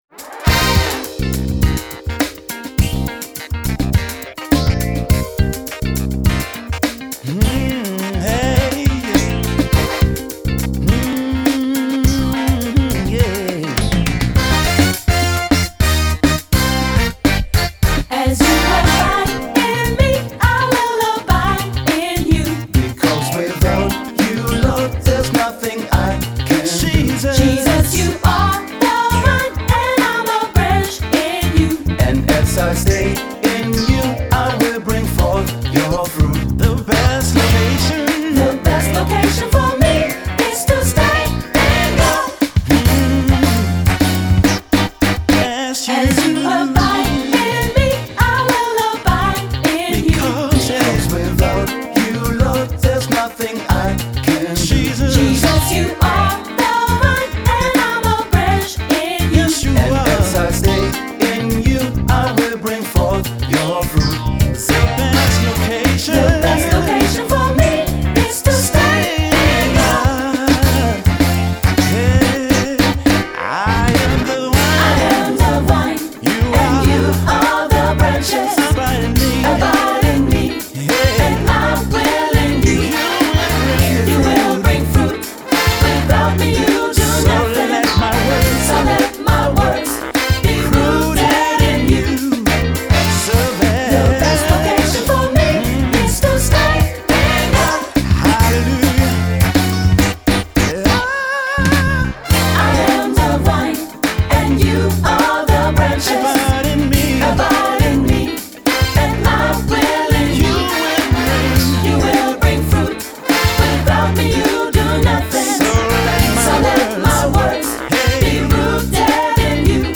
GOSPELNOTEN
• SATB + Piano